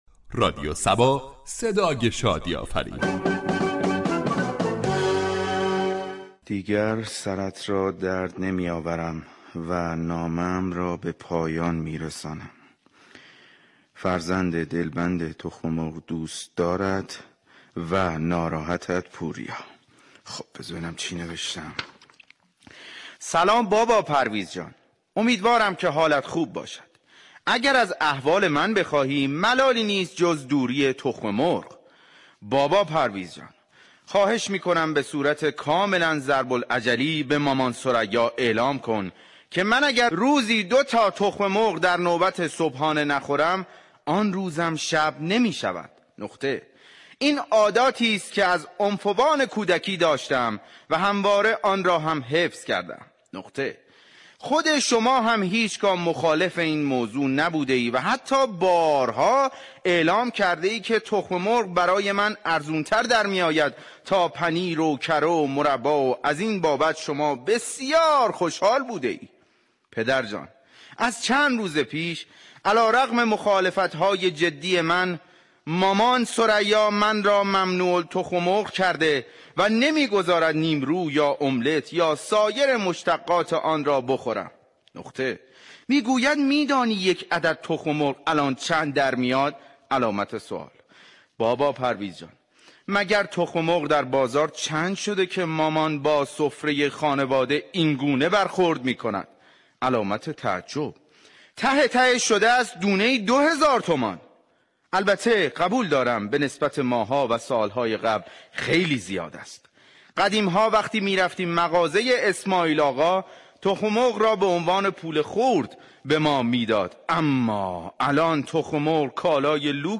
"شهر فرنگ" در بخش نمایشی با بیان طنز به موضوع گرانی تخم مرغ اشاره ای كرده است ،در ادامه شنونده این بخش باشید.